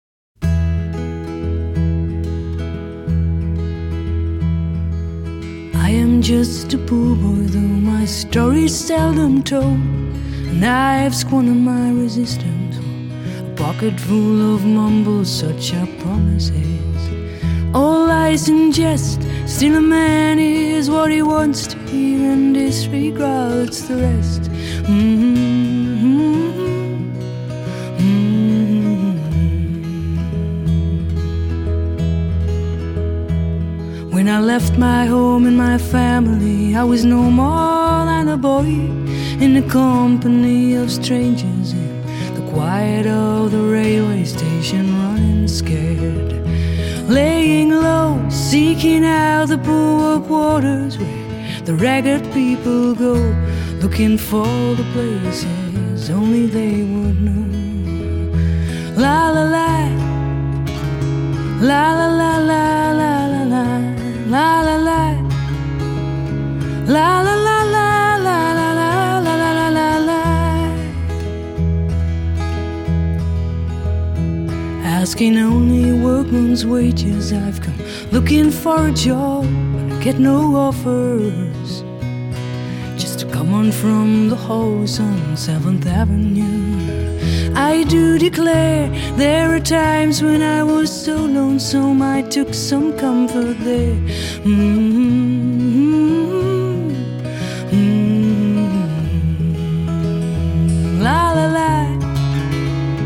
★ 種類涵蓋爵士、古典、流行、民謠等不同曲風，以最優異的設備、最發燒的手法精心錄製！